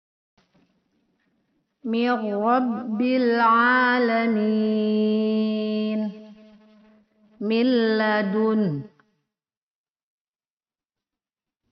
Mim kecil dibaca jelas, cara dengungnya mengarah ke makhraj huruf ba dan membuka bibir sedikit setebal kertas .
Tidak boleh ditahan/ dilamakan, tidak boleh dipantulkan dan tidak boleh diputuskan.